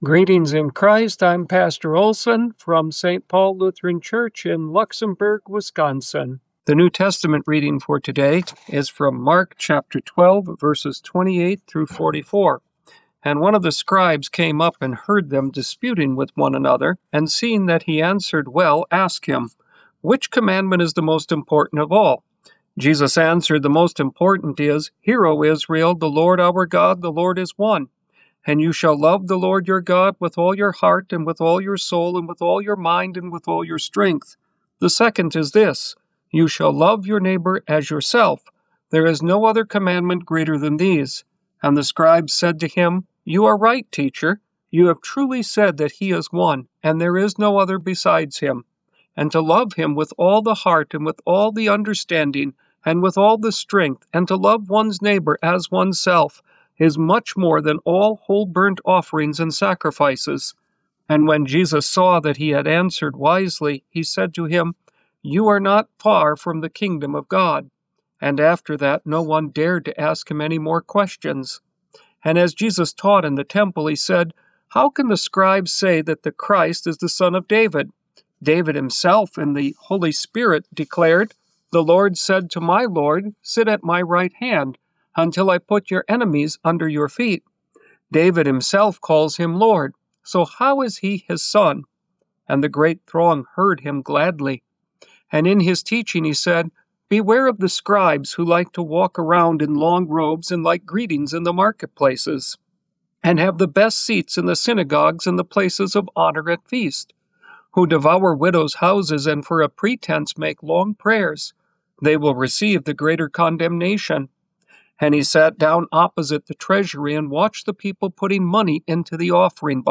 Morning Prayer Sermonette: Mark 12:28-44